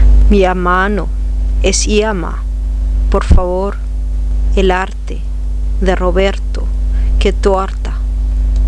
Pronunciación correcta vs pronunciación errada